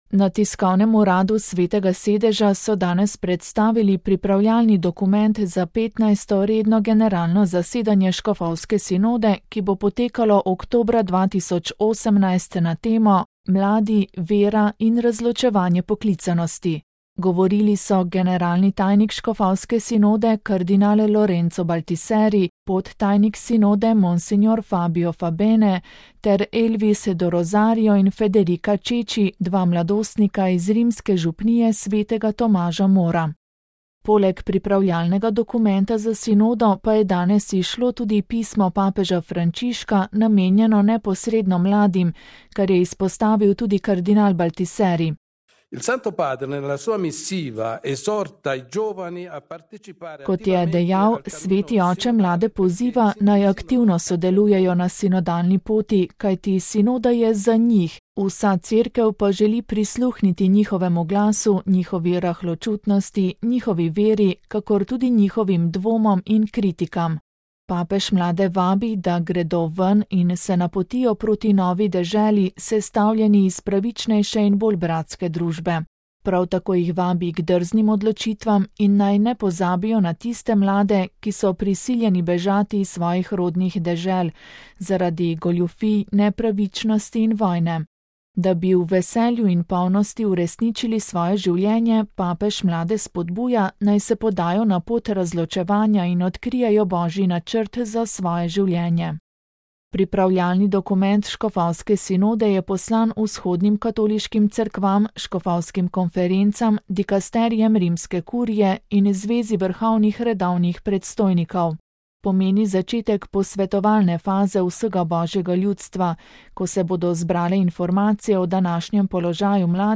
VATIKAN (petek, 13. januar 2017, RV) – Na tiskovnem uradu Svetega sedeža so danes predstavili pripravljalni dokument za 15. redno generalno zasedanje škofovske sinode, ki bo potekalo oktobra 2018 na temo Mladi, vera in razločevanje poklicanosti.